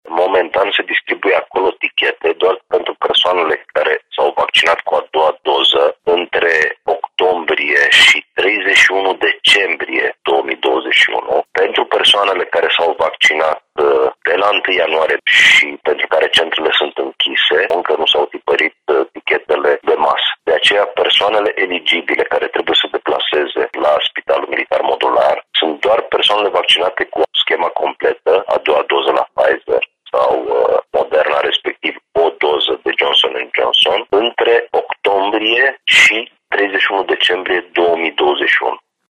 Deocamdată, de aici şi-au ridicat bonurile în valoare de 100 de lei doar aproximativ 10 la sută dintre cei care s-au vaccinat cu schema completă anti-COVID, spune subprefectul Ovidiu Drăgănescu.